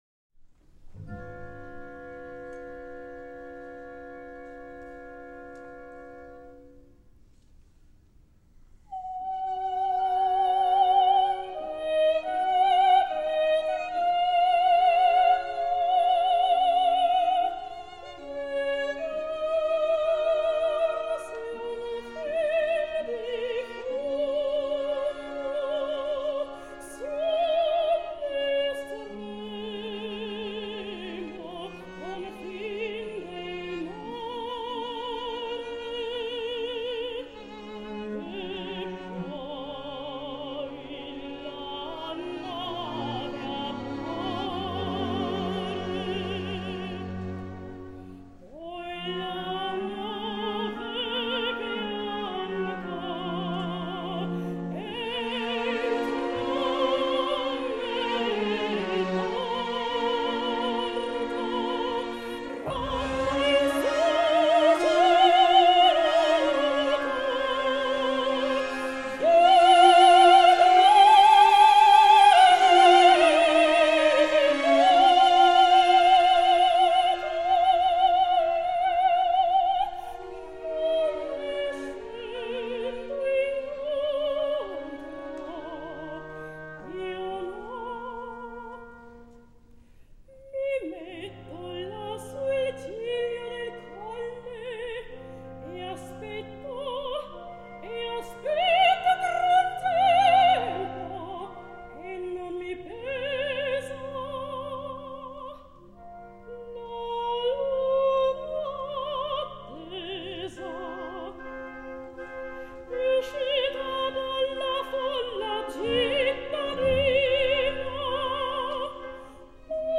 Michèle Crider, Soprano. Giacomo Puccini: Un bel dì vedrem (Cio Cio San’s aria, Act II).
Orchestre de la Suisse Romande. Armin Jordan, conductor.